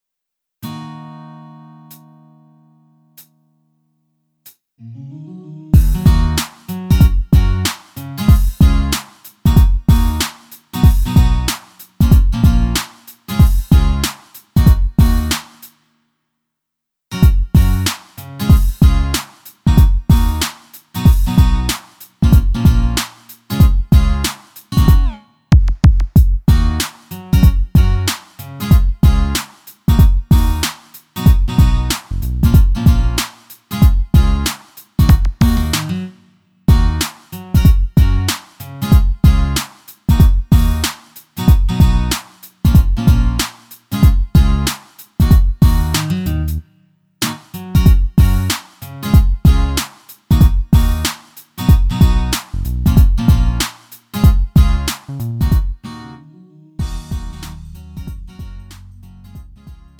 음정 -1키 3:13
장르 구분 Lite MR